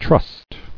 [trust]